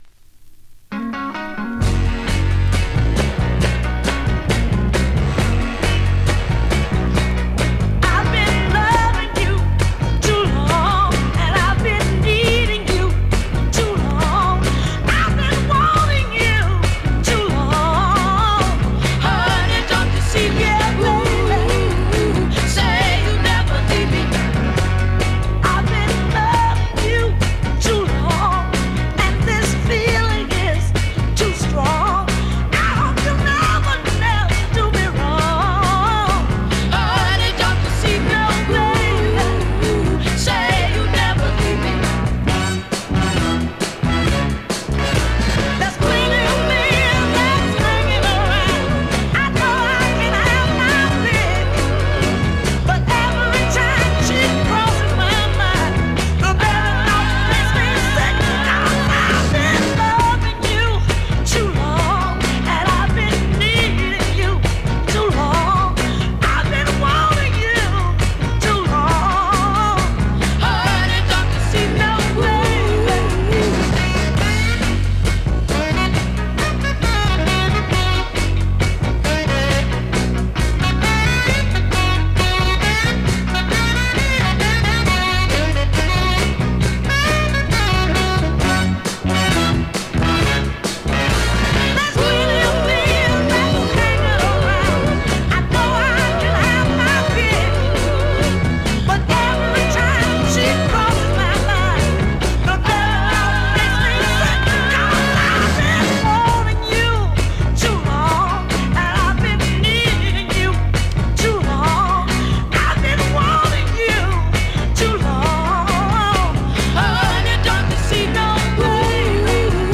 Vinyl has a few light marks plays great .